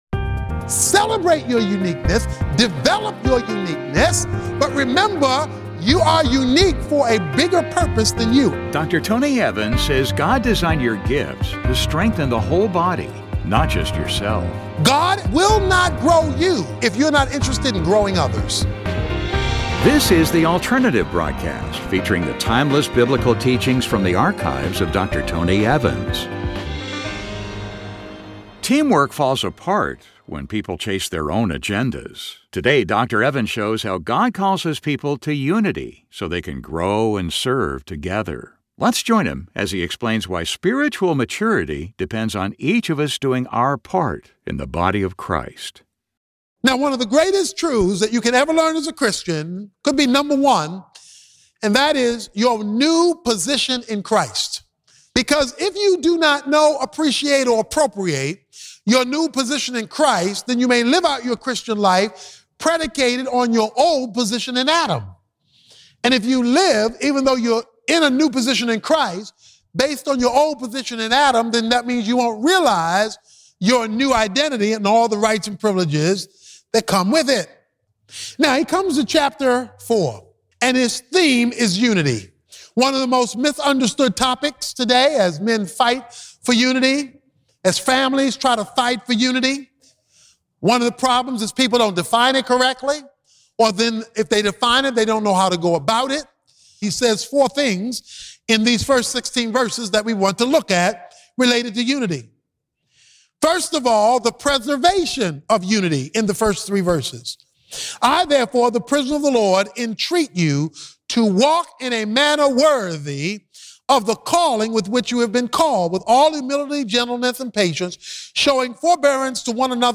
Teamwork falls apart when people chase their own agendas. In this message, Dr.Â Tony Evans explains how unity in the body of Christ fuels spiritual growth and maturity.